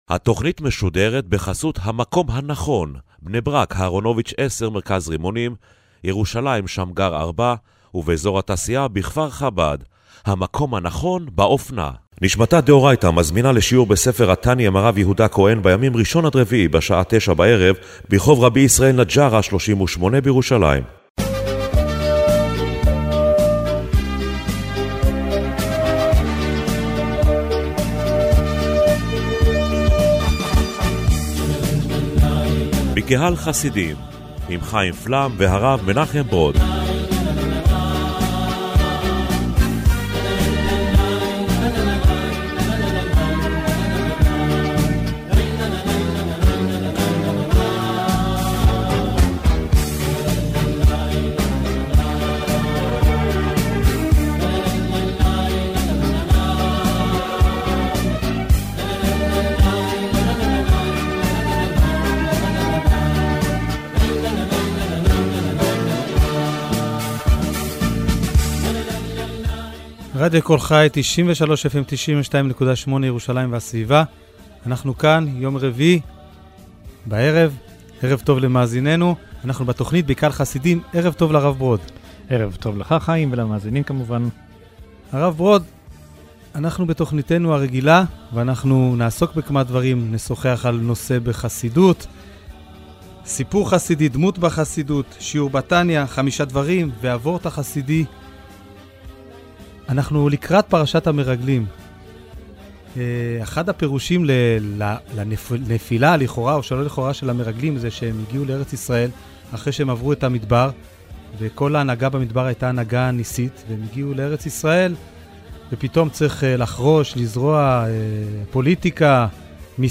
במרכז תכנית הרדיו השבועית בקהל חסידים השבוע עמדה ההתייחסות של החסידות לעבודה בתוך העולם.